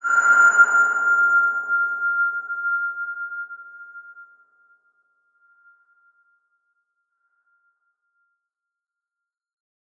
X_BasicBells-F4-ff.wav